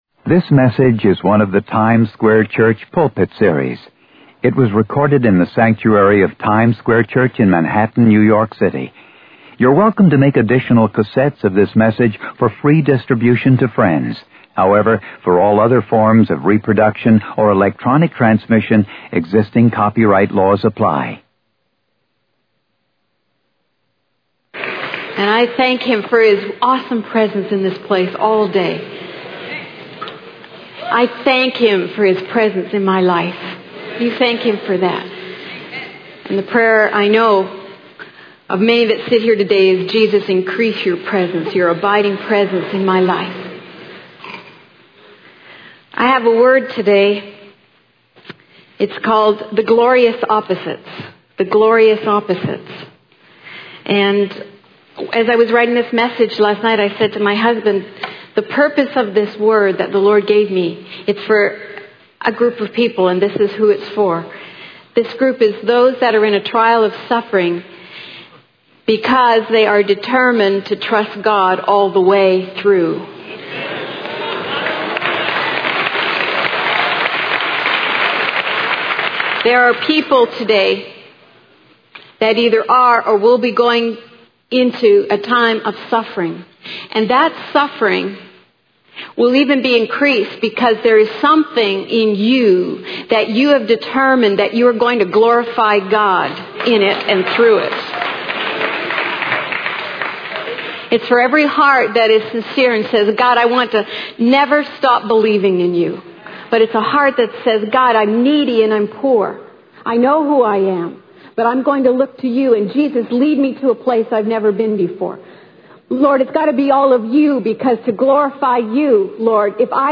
In this sermon, the speaker shares a personal story about his son going skydiving and the intense emotions he experienced during the jump. He relates this to the need for believers to have a deep and unwavering faith in God's salvation.